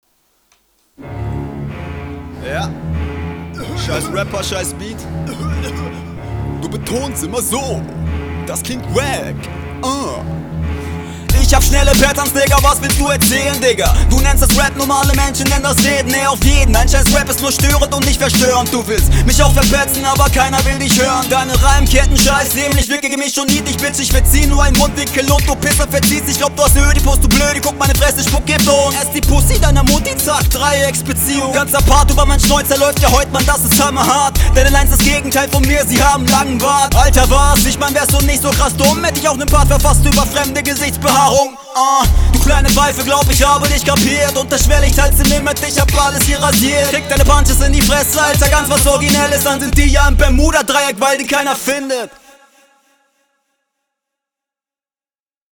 intro wieder gut raptechnisch besser als die hr2 lines auch eher meh marginal besser als …